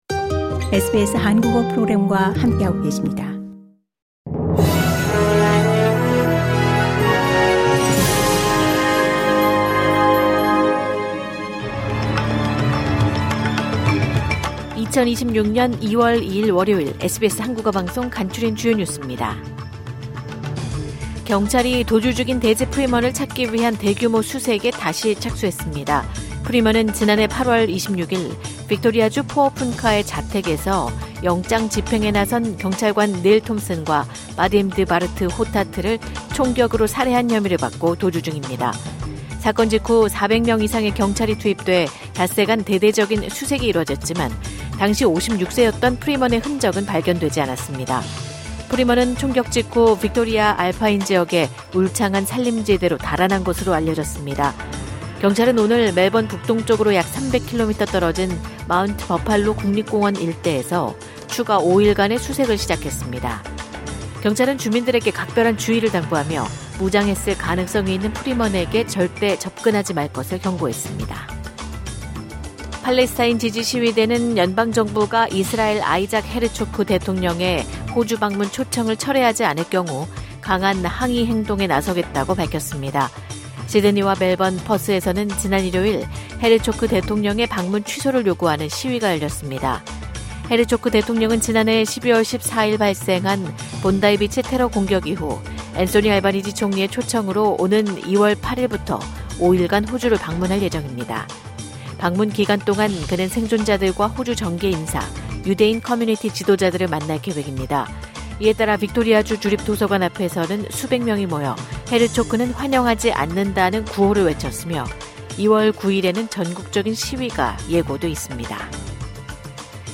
호주 뉴스 3분 브리핑: 2026년 2월 2일 월요일